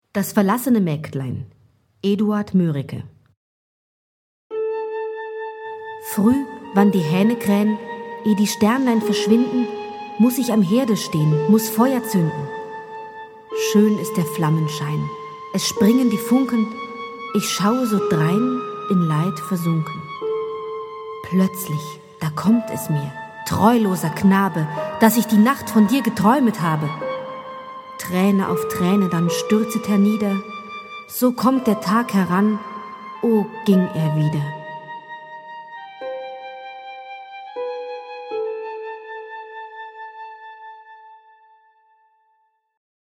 Das verlassene Mägdelein – Gedicht von Eduard Mörike (1804-1875)